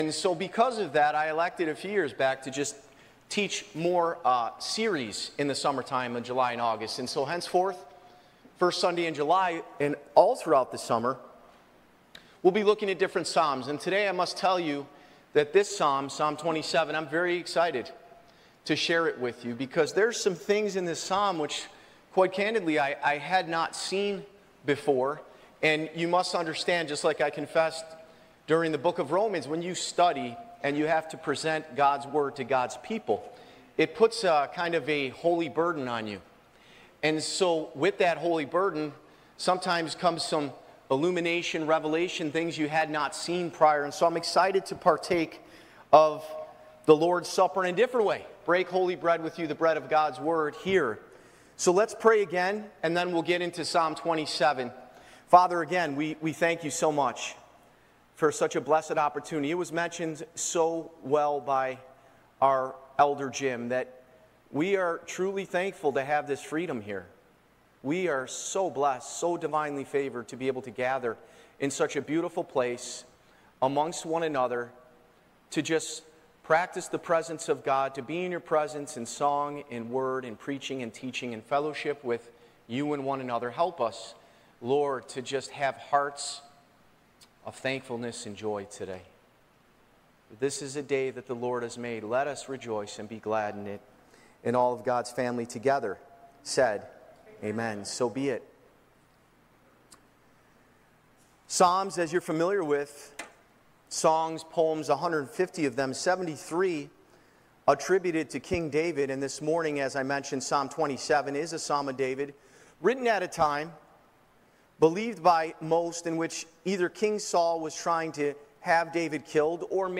Church Location: Spencerport Bible Church
Live Recording